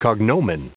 Prononciation du mot cognomen en anglais (fichier audio)
Prononciation du mot : cognomen